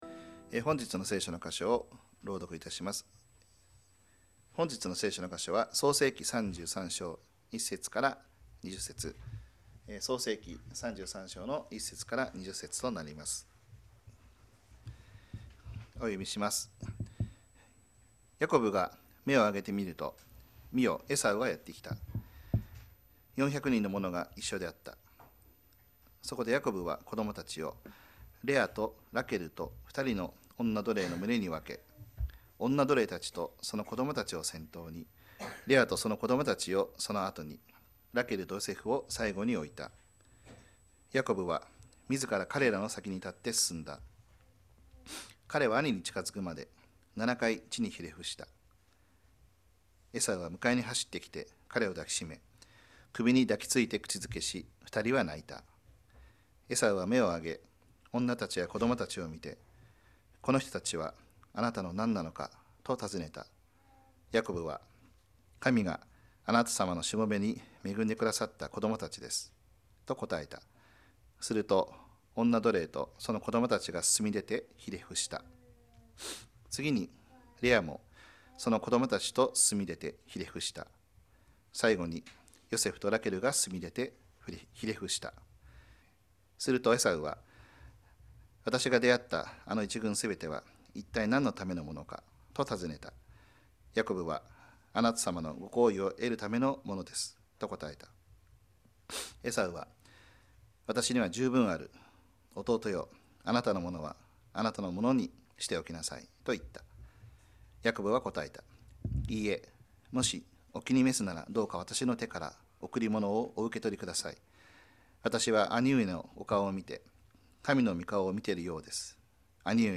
2024年12月1日礼拝 説教 「変えられたヤコブの信仰」 – 海浜幕張めぐみ教会 – Kaihin Makuhari Grace Church